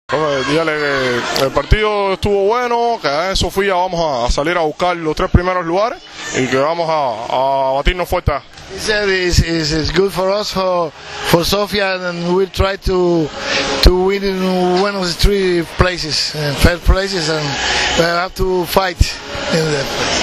IZJAVA VILFREDA LEONA